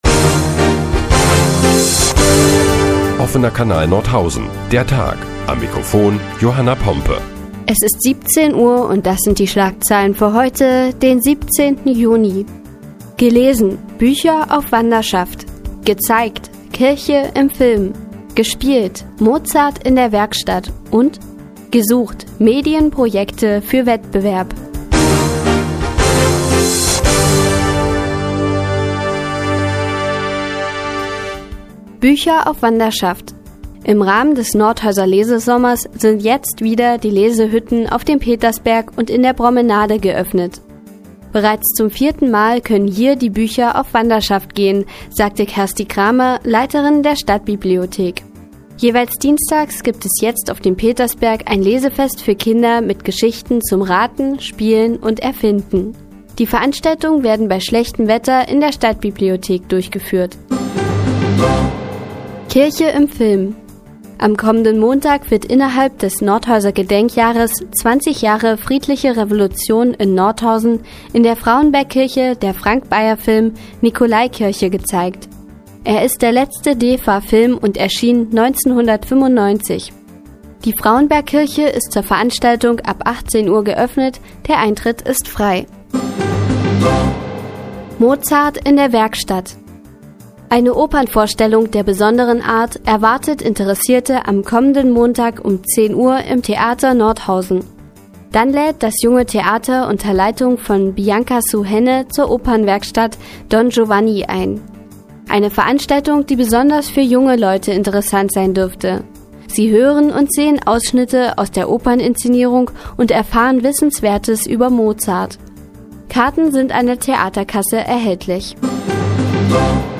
Die tägliche Nachrichtensendung des OKN ist nun auch in der nnz zu hören. Heute geht es unter anderem um Bücher auf Wanderschaft und Mozart in der Werkstatt.